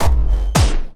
poly_explosion_blackhole3.wav